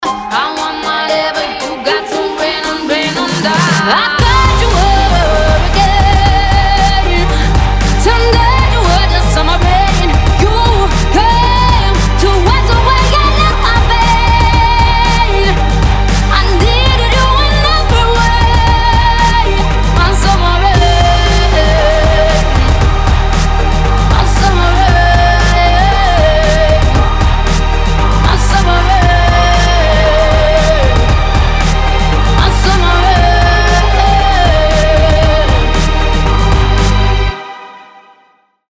• Качество: 320, Stereo
поп
Blues